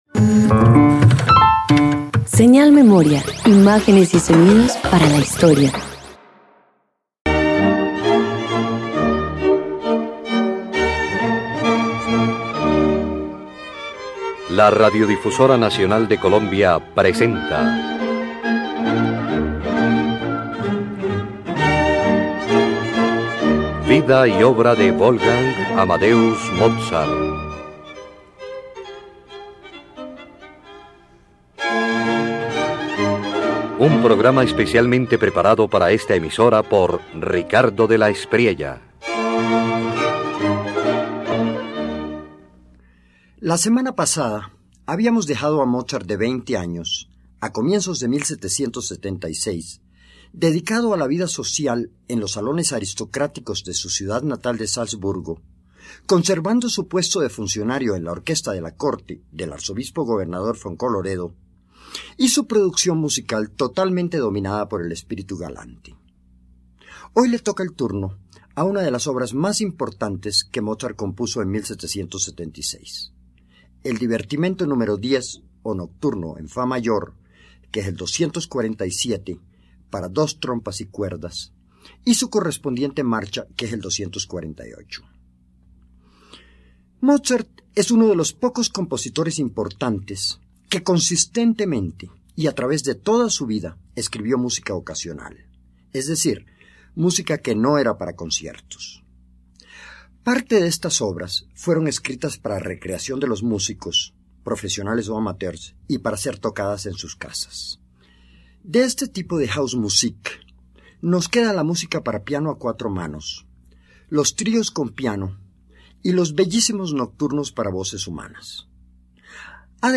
En el verano de 1776, Mozart compone el Divertimento n.º 10 en fa mayor K247 y su marcha K248, piezas luminosas y refinadas creadas para la condesa Lodron. En ellas, el joven compositor alcanza una ligereza y elegancia típicamente mozartianas.
Música instrumental